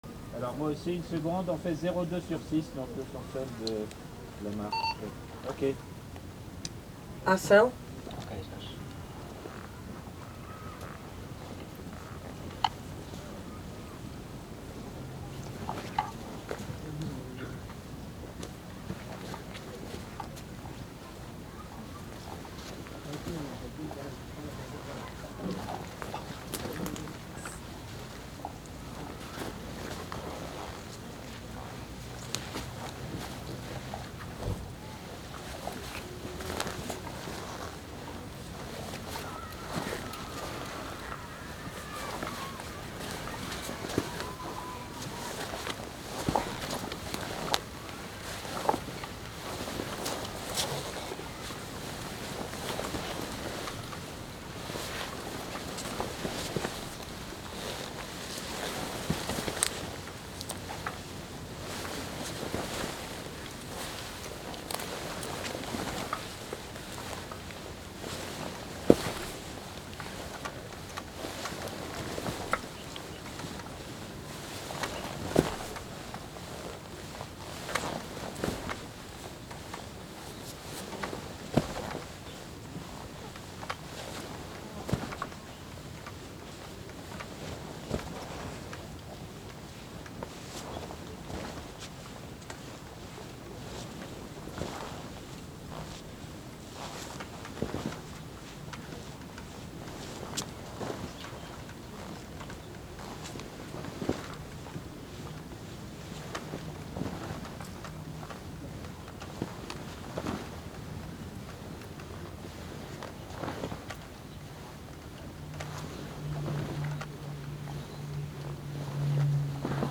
La République des enfants – W2 / 6 w1 – stéréo MS décodé L&R
Mais dans cette unique prise, les enfants ne sont plus en rythme et cela ne convient pas au son. Nous refaisons alors la même prise en son seul, sans la caméra, sans le bébé, avec le même dispositif de micros, mais un avion vient en troubler le fond sans compter des gens qui parlent dans le fond.